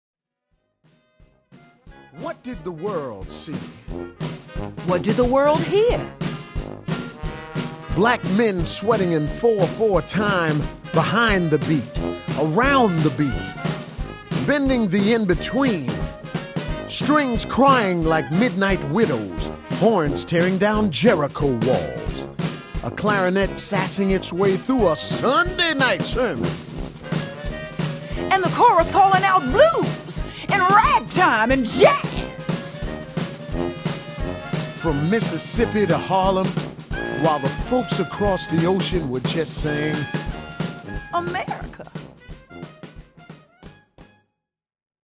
ALA Odyssey Award for Excellence in Audiobook Production "Exuberant music, powerful narration, and image-filled poetry combine to create this extraordinary recording, winner of ALA's first Odyssey Award for excellence in audiobook production."